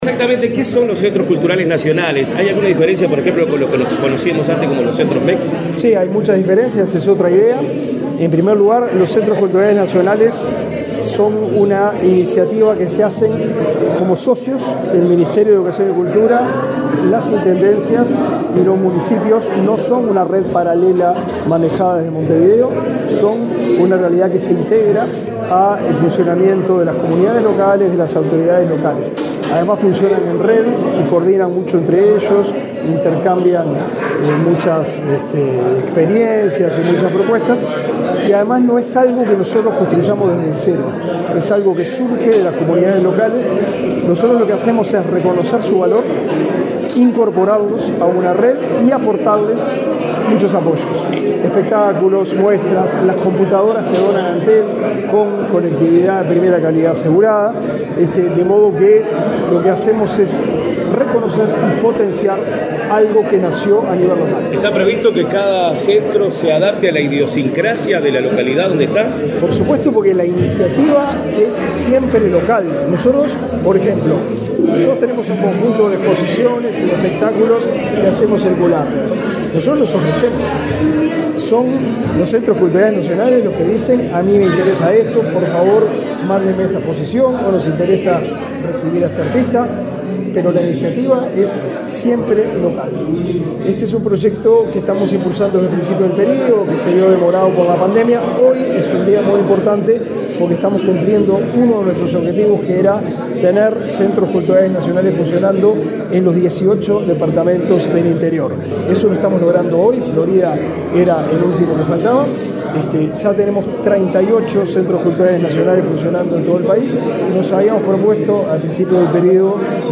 Declaraciones del ministro de Educación y Cultura, Pablo da Silveira | Presidencia Uruguay
El ministro de Educación y Cultura, Pablo da Silveira, dialogó con la prensa, luego de participar en la ceremonias de nominación de la sala de